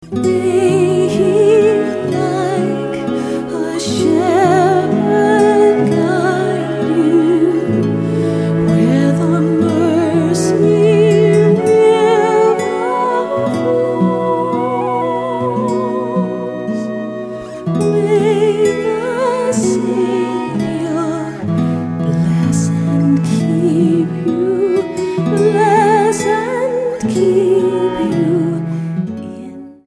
STYLE: Celtic
traditional Celtic instrumentation with soothing lullabies